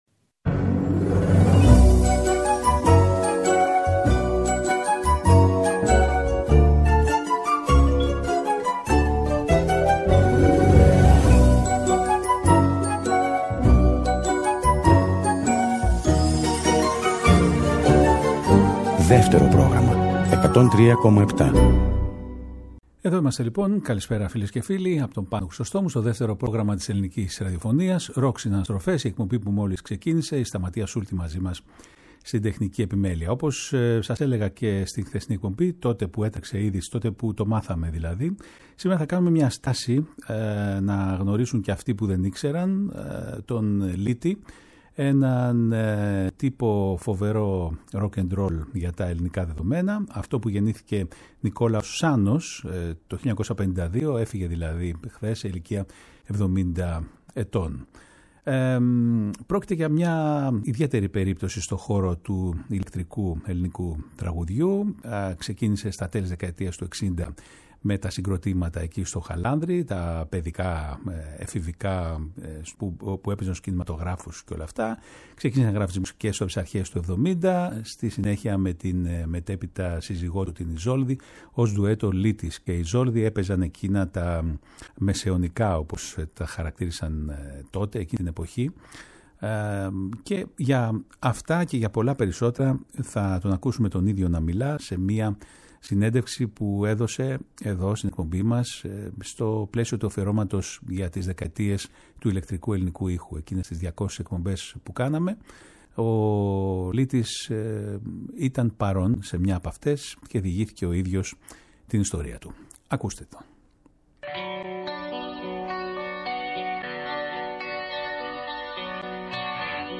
Ακούμε τον ίδιο να μιλάει σε μια παλιότερη συνέντευξη που είχε δώσει στην εκπομπή